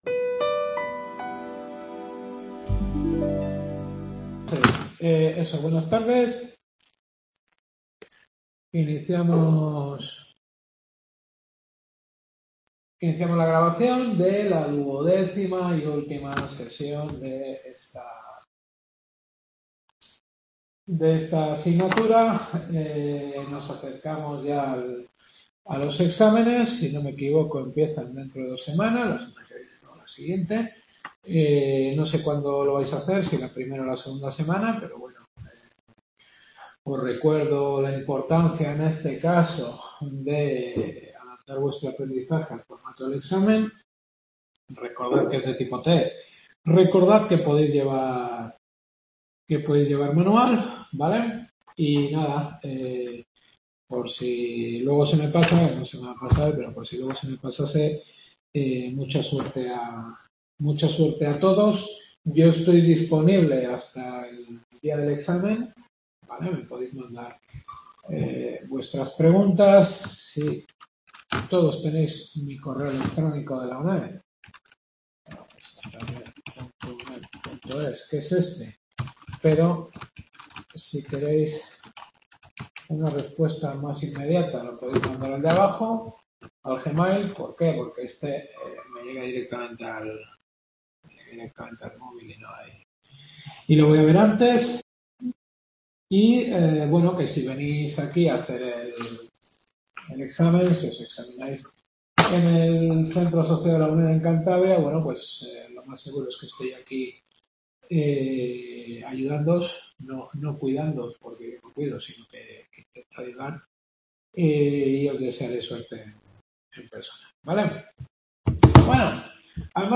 Derecho Administrativo Europeo. Duodécima clase… | Repositorio Digital